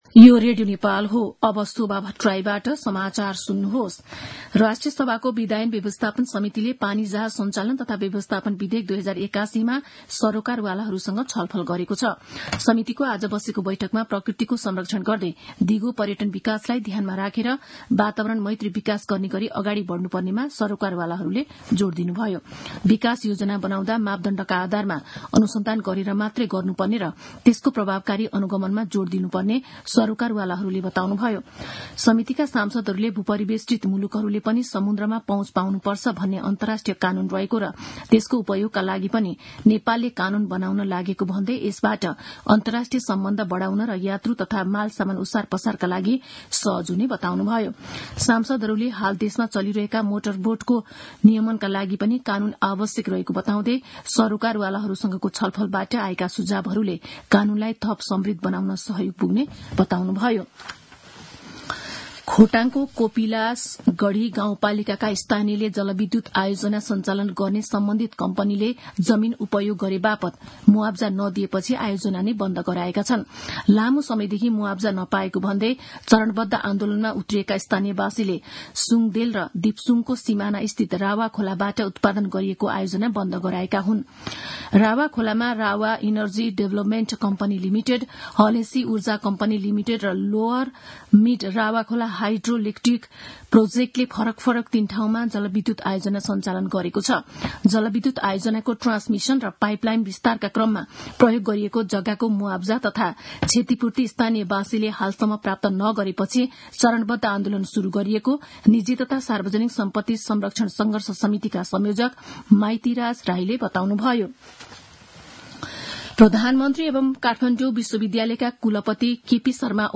दिउँसो ४ बजेको नेपाली समाचार : ४ चैत , २०८१